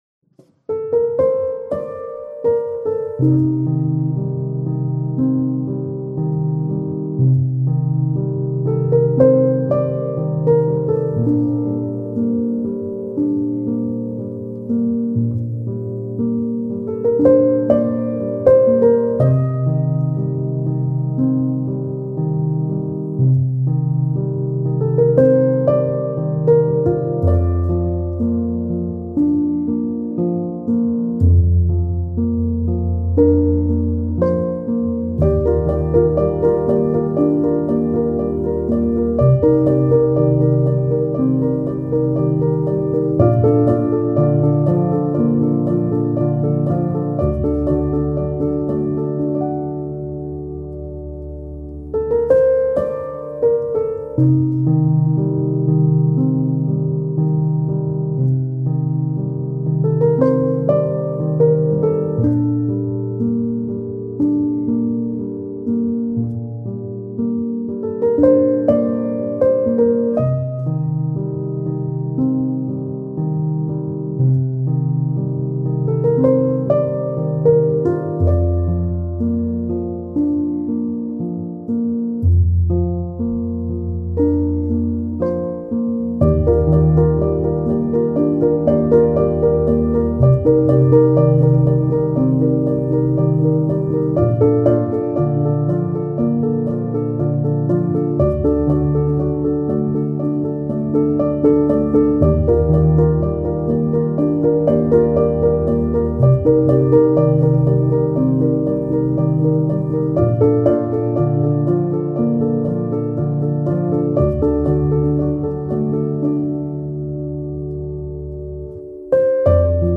Breathtaker-Piano-Solo.mp3